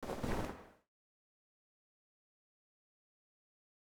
close_bag_master_1.wav